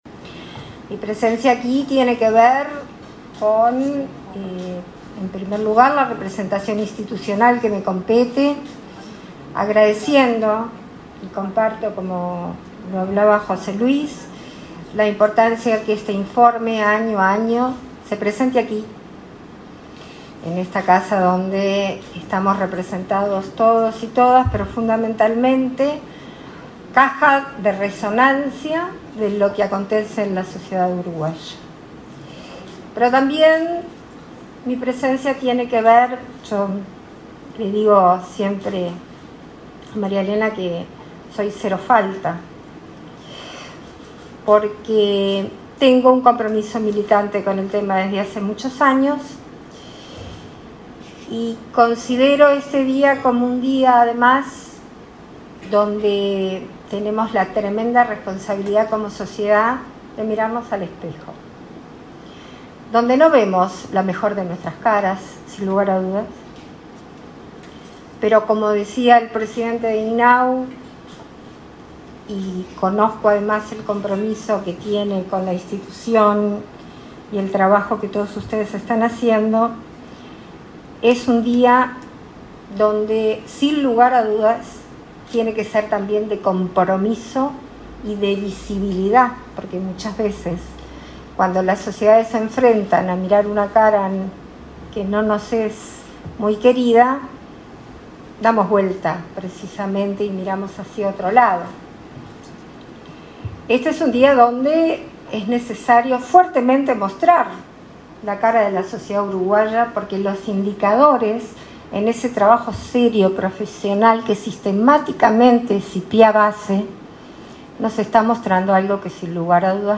Palabras de autoridades en acto de INAU
Palabras de autoridades en acto de INAU 25/04/2023 Compartir Facebook X Copiar enlace WhatsApp LinkedIn En el marco del Día Internacional de Lucha contra el Maltrato y el Abuso sexual hacia Niñas, Niños y Adolescentes, la vicepresidenta de la República, Beatriz Argimón, y el titular del Instituto del Niño y el Adolescente del Uruguay (INAU), Pablo Abdala, participaron en la presentación del informe anual de gestión de 2022 sobre esta temática.